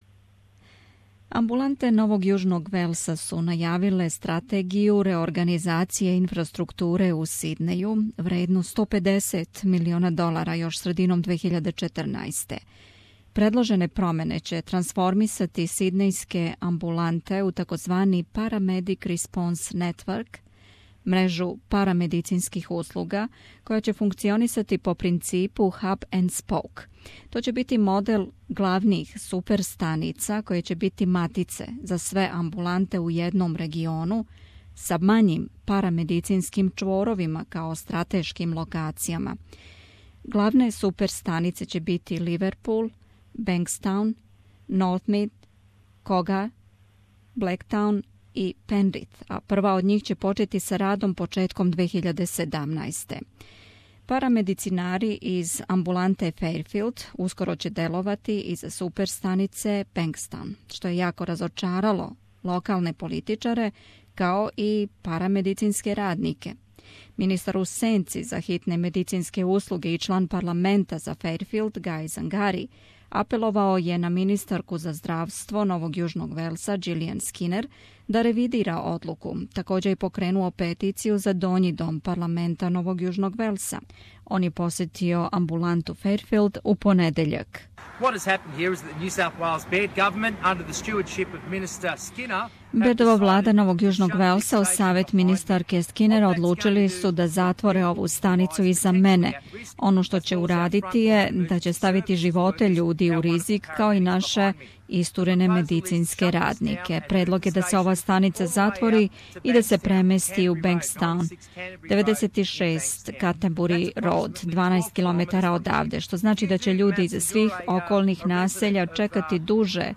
У разговору са њим сазнали смо више о овом проблему.